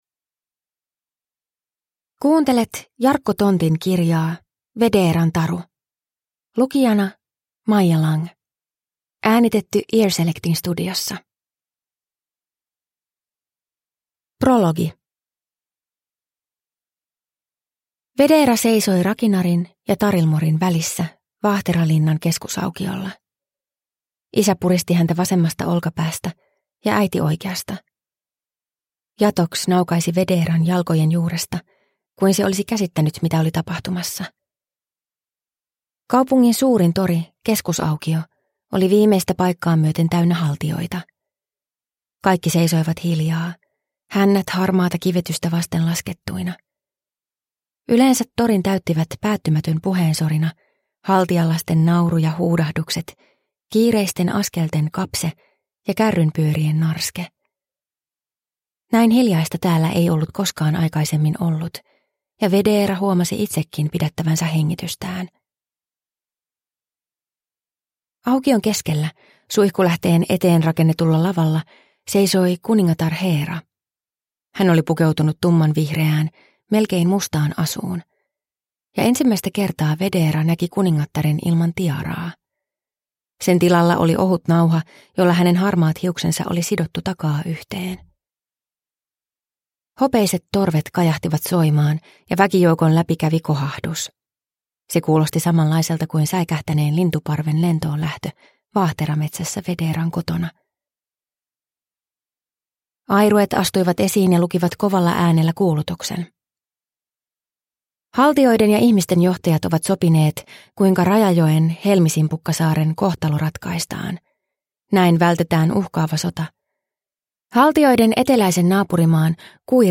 Vedeeran taru – Ljudbok